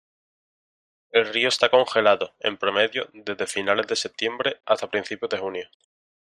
Read more Noun Verb promediar to bisect to determine the average Read more Frequency C1 Hyphenated as pro‧me‧dio Pronounced as (IPA) /pɾoˈmedjo/ Etymology Borrowed from Latin - In summary Borrowed from Latin pro medio (“average”).